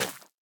Minecraft Version Minecraft Version snapshot Latest Release | Latest Snapshot snapshot / assets / minecraft / sounds / item / plant / netherwart6.ogg Compare With Compare With Latest Release | Latest Snapshot
netherwart6.ogg